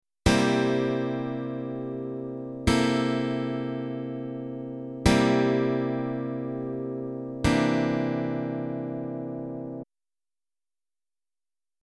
• Measure 1: C7 rooted on the 6th string, 8th fret.
• Measure 2: C13 rooted on the 6th string, 8th fret.
• Measure 3: C7 rooted on the 5th string, 3rd fret.
• Measure 4: C9 rooted on the 5th string, 3rd fret.
12 Bar Blues Chords
12barblues_chords.mp3